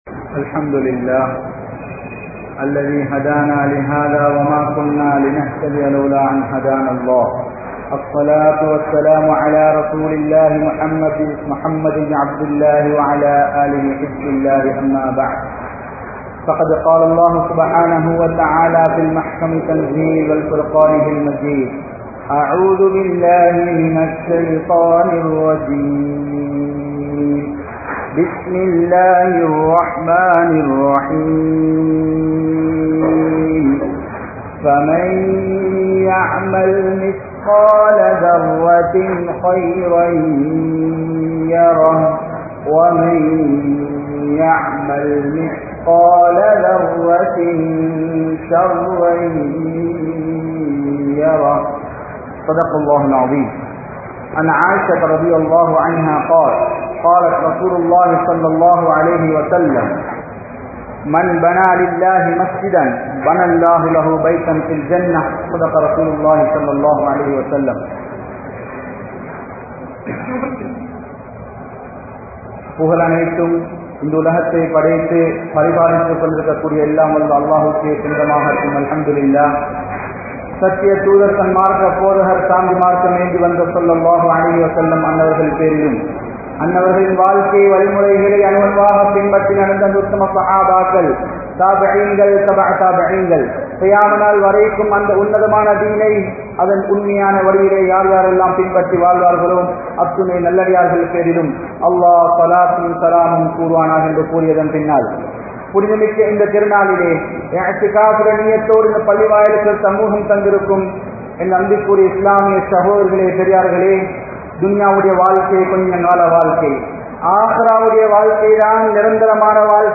Masjidhin Niruvaahaththitku Thahuthiyaanavarhal Yaar? (மஸ்ஜிதின் நிருவாகத்திற்கு தகுதியானவர்கள் யார்?) | Audio Bayans | All Ceylon Muslim Youth Community | Addalaichenai
Mallawapitiya Jumua Masjidh